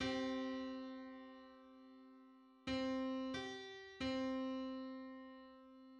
Just: 189:128 = 386.31 cents.
Public domain Public domain false false This media depicts a musical interval outside of a specific musical context.
Hundred-eighty-ninth_harmonic_on_C.mid.mp3